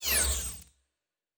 Sci-Fi Sounds / Electric
Device 4 Stop.wav